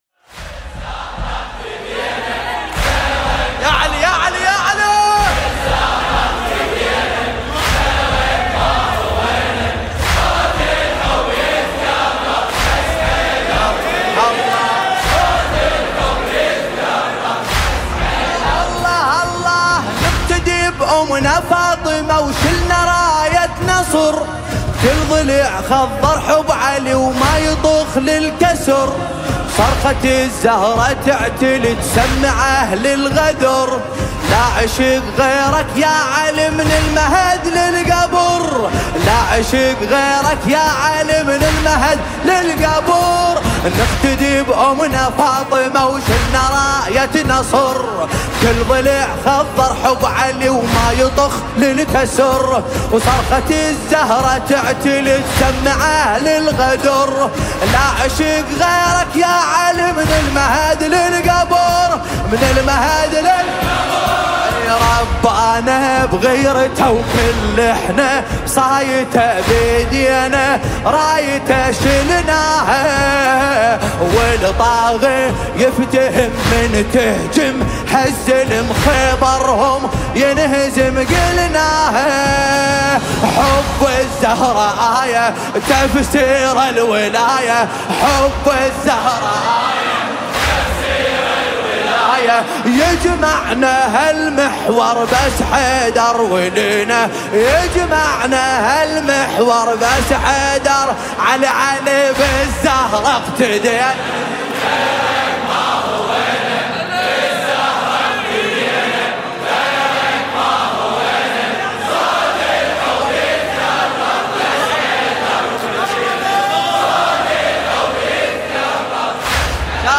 مداحی عربی دلنشین